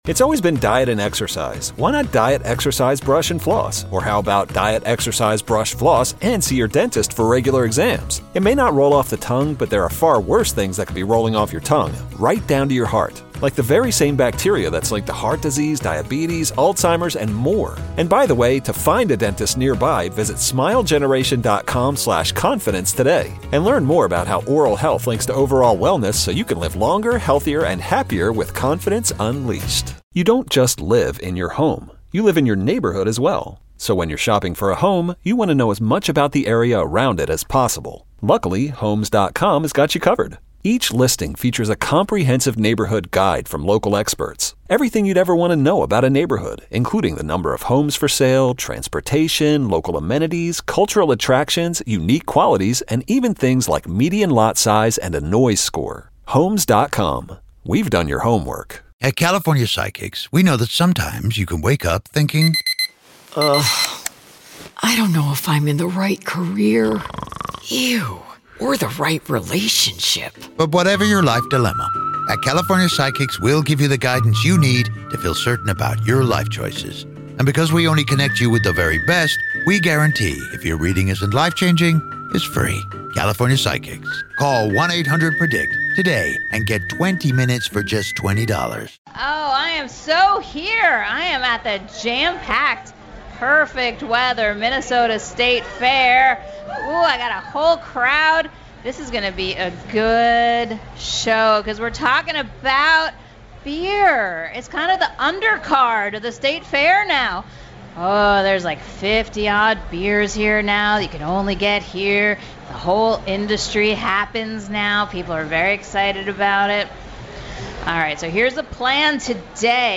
a State Fair Beer show from the WCCO Fair booth
join the show from the porch.